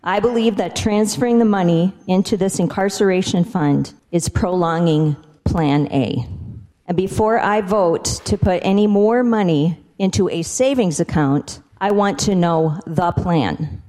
Canton Representative Karla Lems is opposed the amendment and the project.
house-prison-debate-lems.mp3